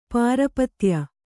♪ pārapatya